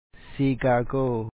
síikháakòo Chicago